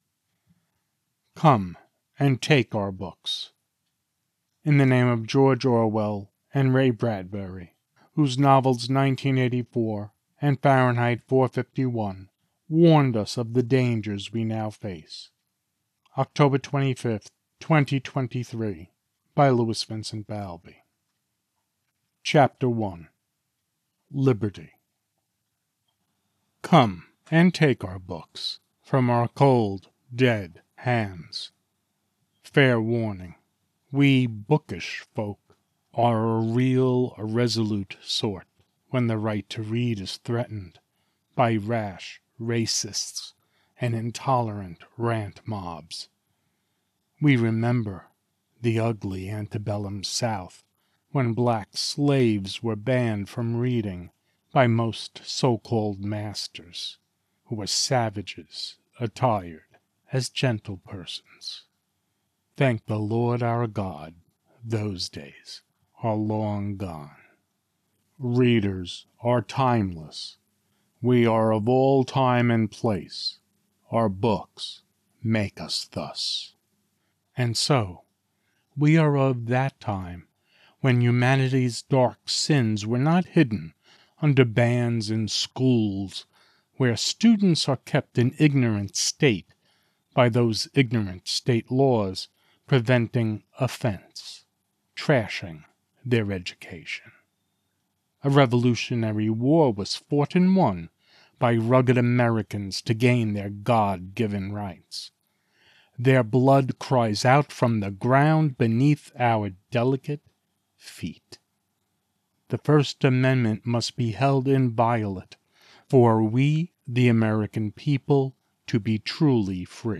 Come and Take Our Books From Our Cold Dead Hands Poem